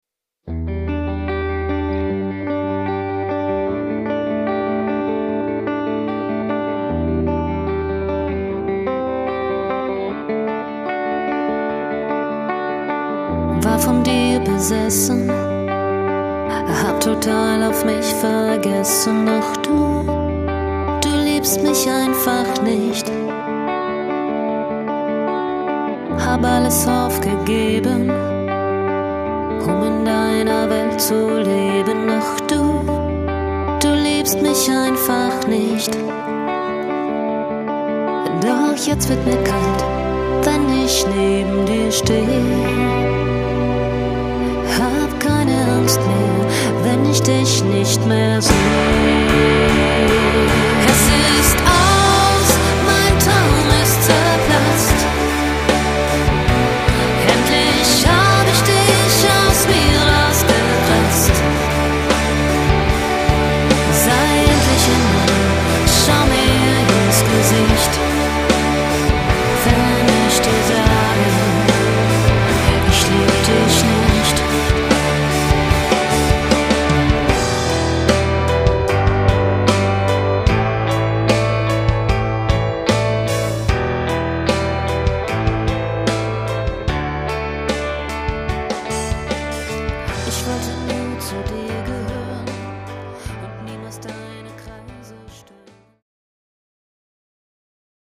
lead & backing vocals
keyboards, guitars, bass, orchestration, vocals guests
solo guitars
drums & percussion